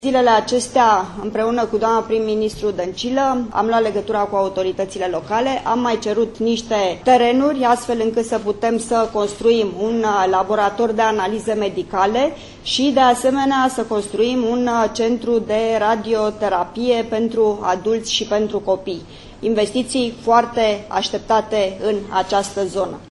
Aflată alături de studenți și cadre didactice ale UMF din Craiova, cu ocazia deschiderii anului universitar 2018 – 2019, ministrul Muncii și Justiției Sociale, Lia Olguța Vasilescu a anunțat că sistemul medical municipal va beneficia de noi investiții.